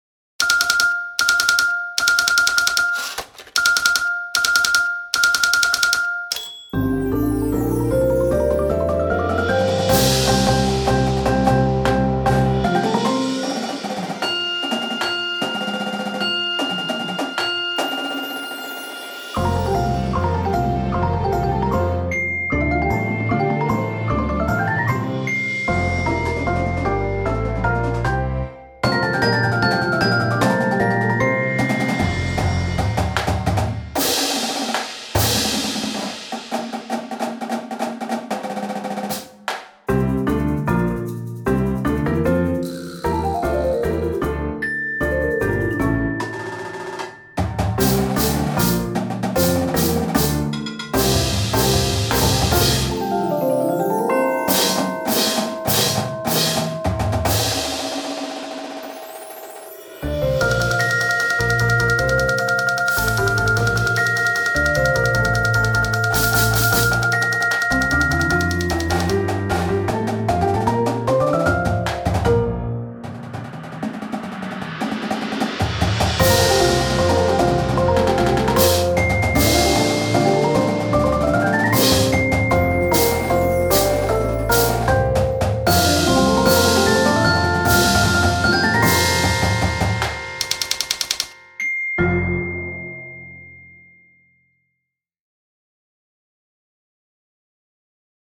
Instrumentation: Full Ensemble Percussion